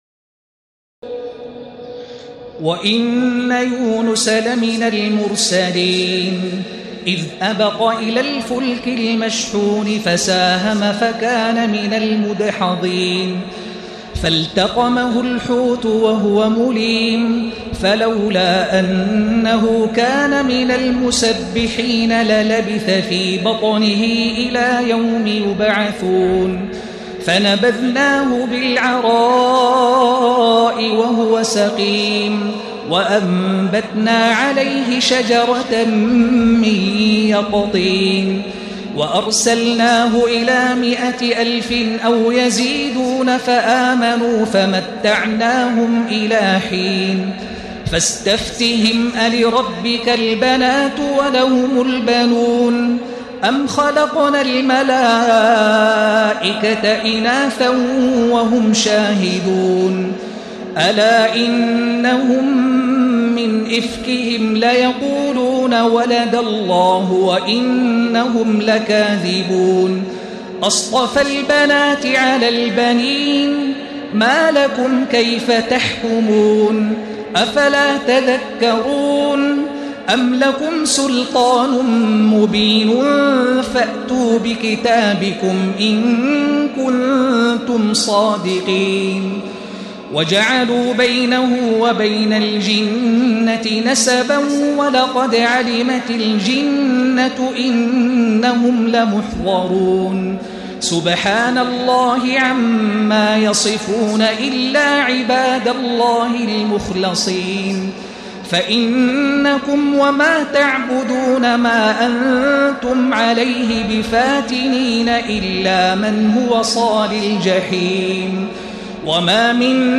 تراويح ليلة 22 رمضان 1436هـ من سور الصافات (139-182) وص و الزمر (1-31) Taraweeh 22 st night Ramadan 1436H from Surah As-Saaffaat and Saad and Az-Zumar > تراويح الحرم المكي عام 1436 🕋 > التراويح - تلاوات الحرمين